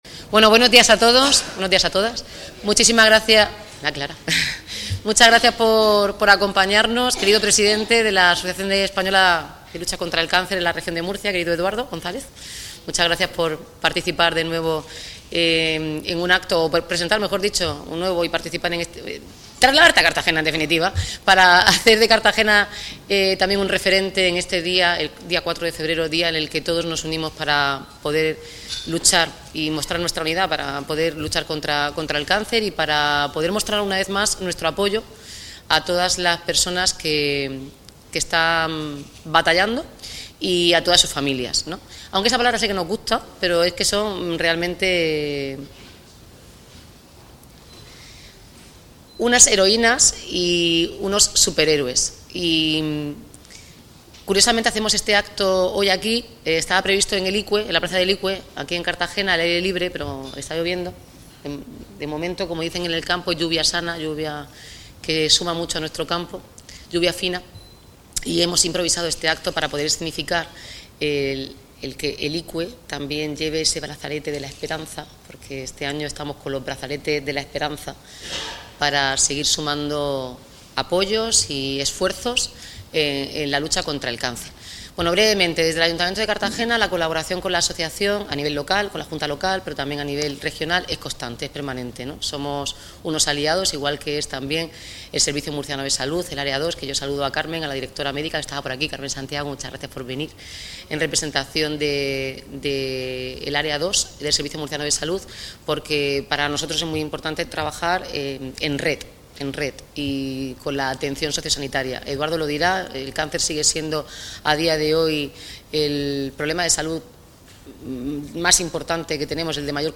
Audio: Declaraciones de la alcaldesa Noelia Arroyo.